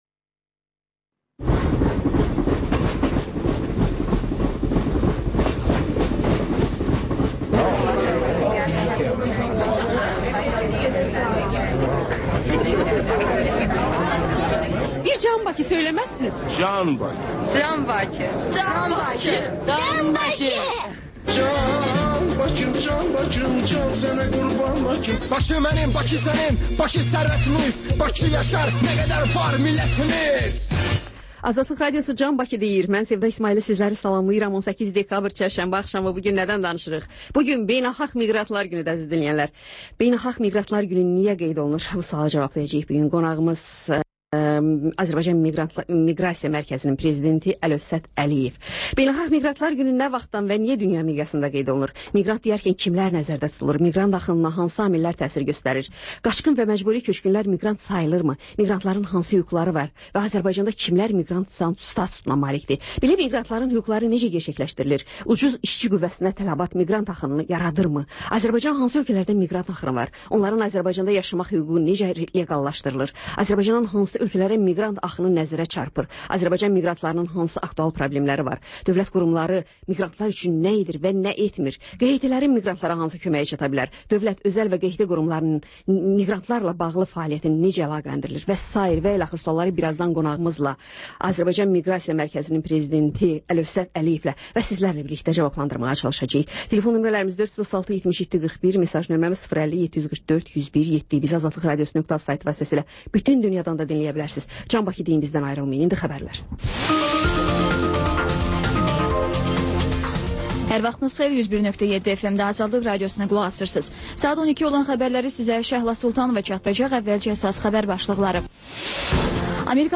Xəbərlər, sonra CAN BAKI verilişi: Bakının ictimai və mədəni yaşamı, düşüncə və əyləncə həyatı…